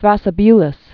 (thrăsə-byləs) Died c. 388 BC.